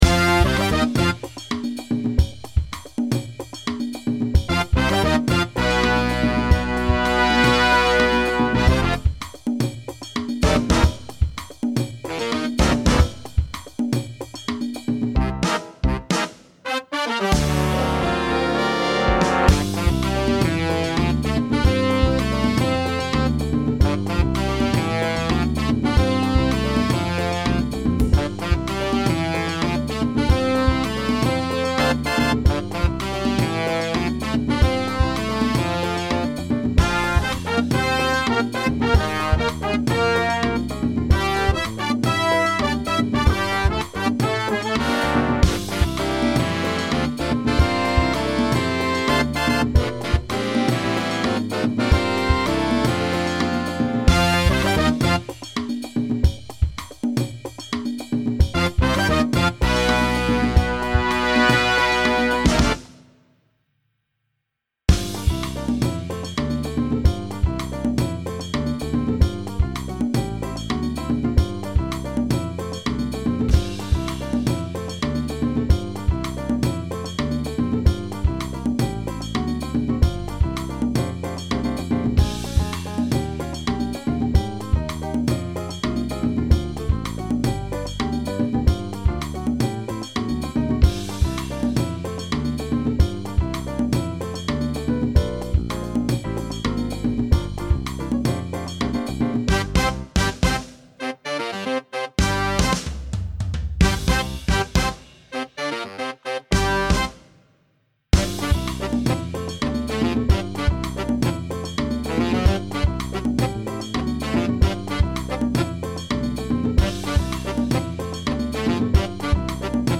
Big Band Instrumental